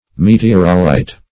Search Result for " meteorolite" : The Collaborative International Dictionary of English v.0.48: Meteorolite \Me`te*or"o*lite\ (?; 277), n. [Meteor + -lite : cf. F. m['e]t['e]orolithe.]
meteorolite.mp3